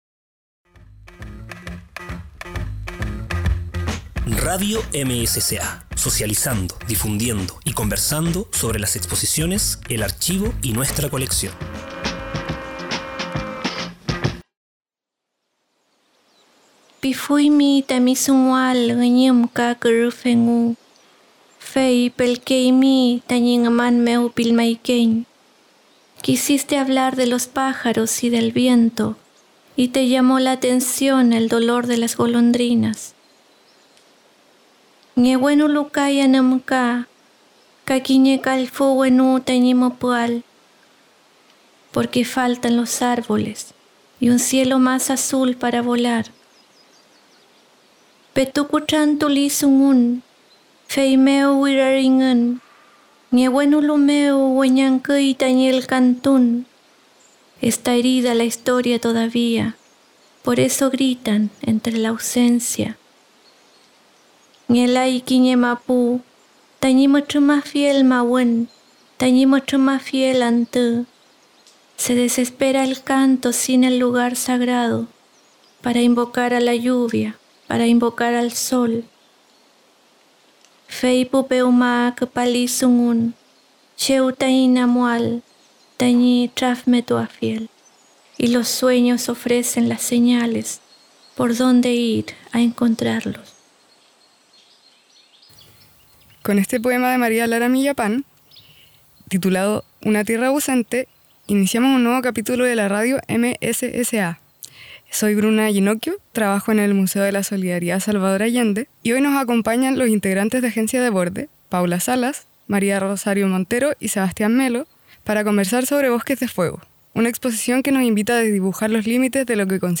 Resumen: Conversamos junto al colectivo Agencia de Borde sobre la exposición Bosques de Fuego y la investigación previa a ella. Abordamos distintos fenómenos socioambientales, como la construcción histórica y cultural de los monocultivos en Chile, la intervención del territorio y el binarismo entre las nociones de extractivismo y conservación, invitándonos a complejizar nuestras percepciones en torno a las especies introducidas.